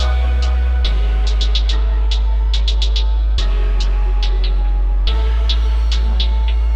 Jumpman Ending Loop.wav